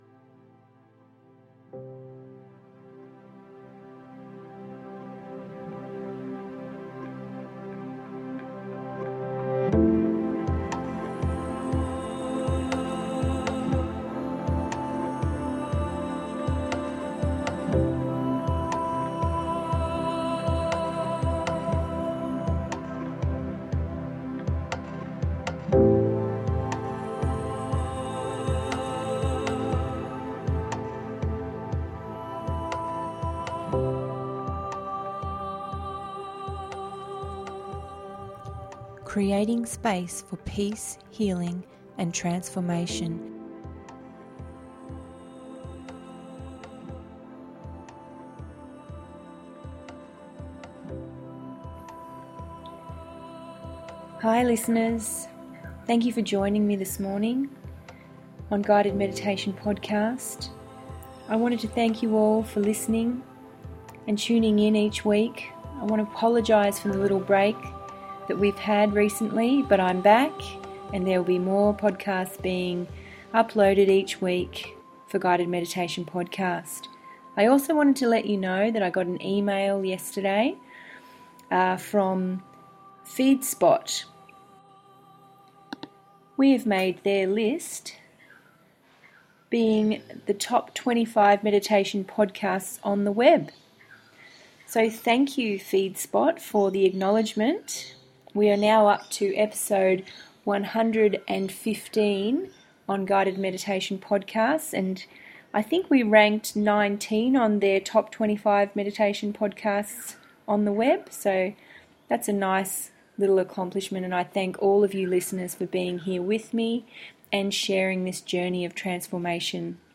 Today's Guided Meditation on Guided Meditation Podcast is getting us ready for the New Moon. There is nothing better than setting up your energetic protection and cutting ties that no longer serve you as you head into the new moon phase.